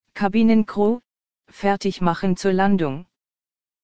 CrewSeatsLanding.ogg